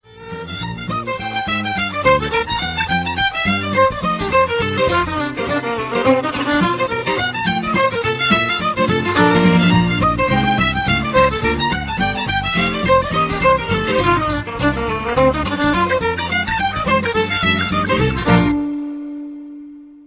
fiddle
and guitar and piano